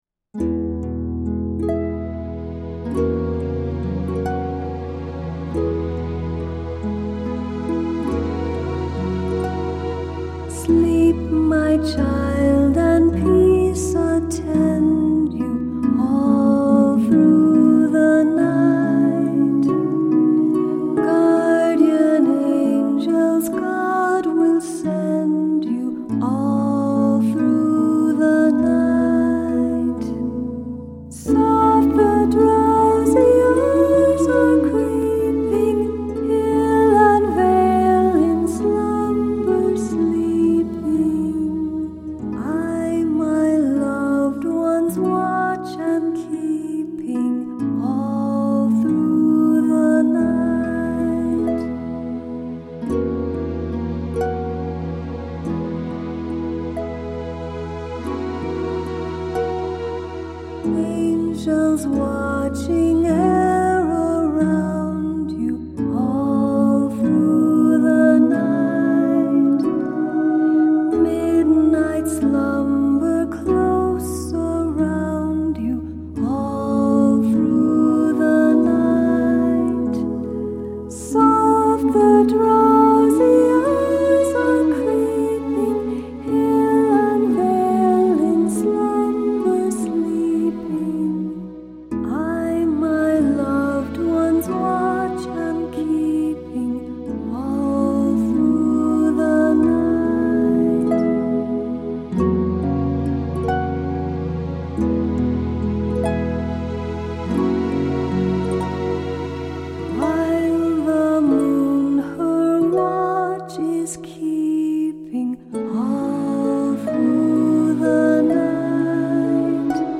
Song Samples in mp3 format